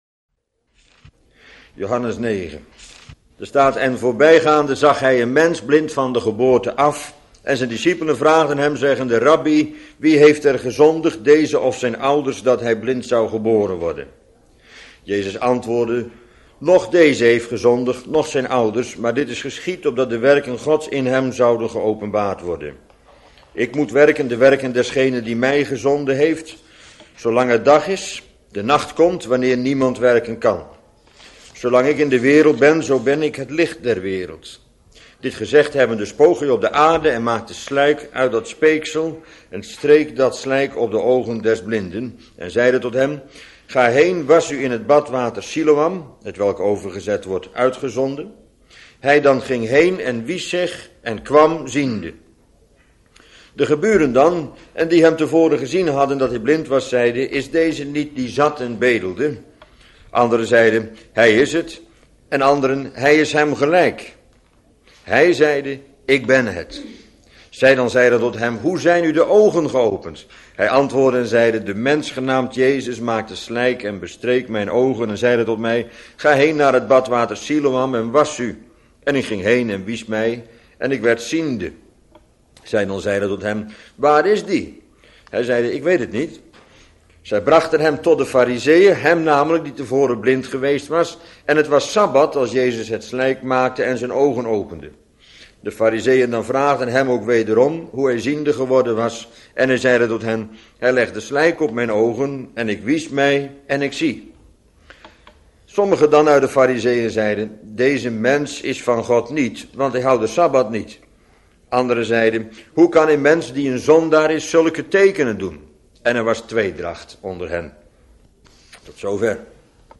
Bijbelstudie lezing onderwerp: De blindgeborene (Joh.9)